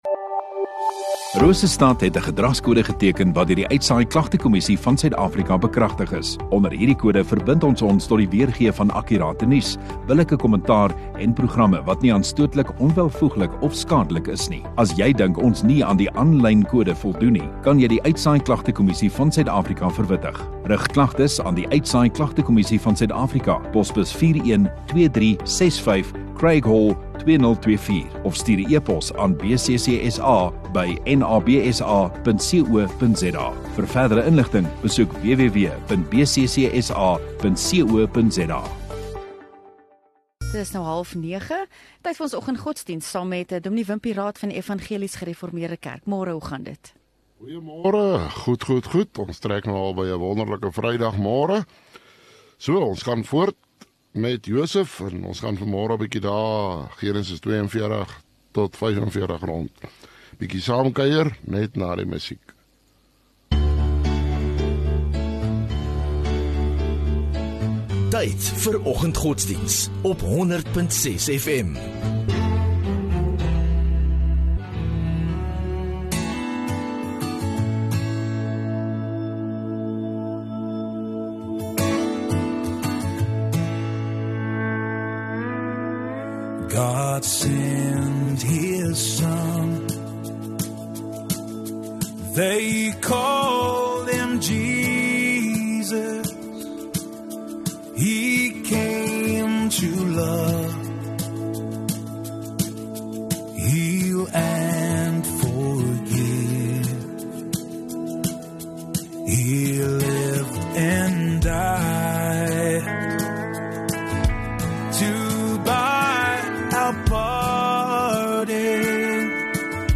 14 Jun Vrydag Oggenddiens